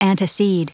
Prononciation du mot antecede en anglais (fichier audio)
Prononciation du mot : antecede